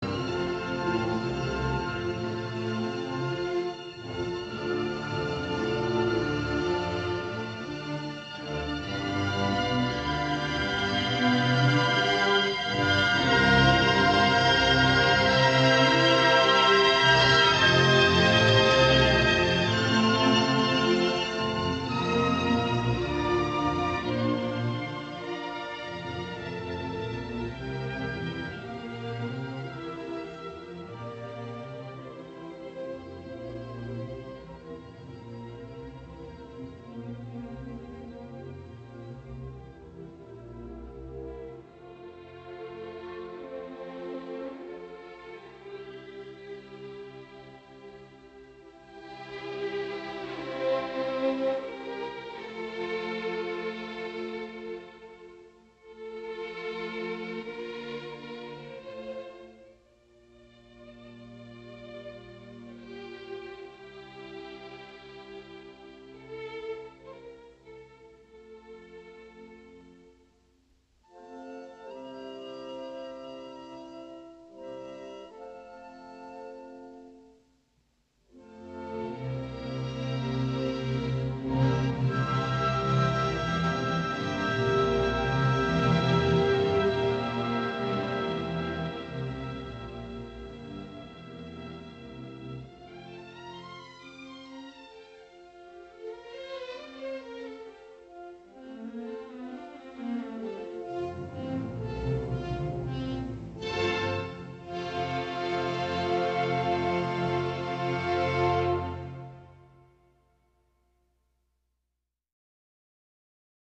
Arranging Symphonic, Vocal, and Piano Works for performance on Carillon
Forceful 16th note triplets in strings are a very SPECIFIC type of tremolo.
Bass bells get to be the big story and should come out strong, indicated by marcati.
mm 117-126, Gently bringing climax down, keep tremolos gentle as you decrescendo. No more marcati in bass.